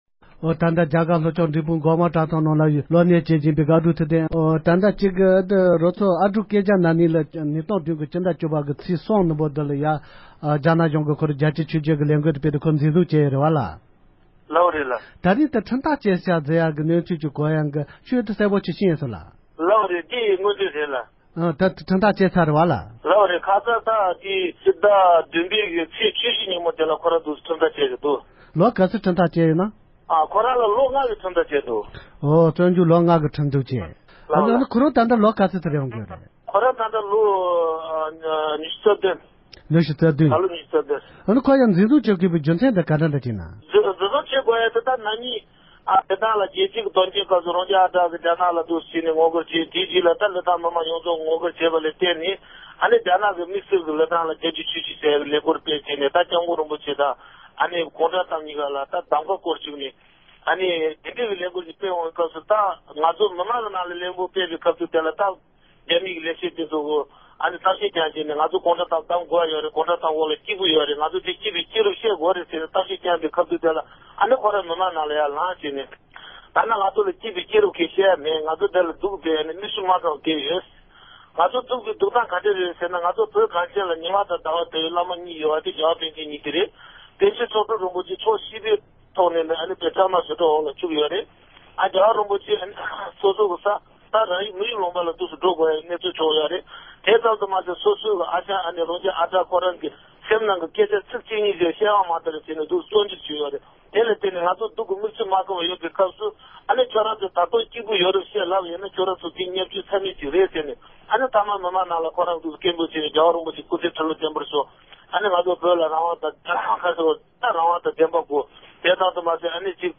དམིགས་བསལ་གསར་འགྱུར